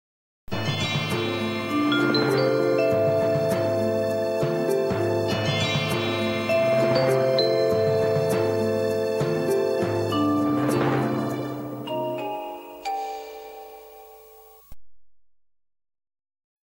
Jingle | CINEMA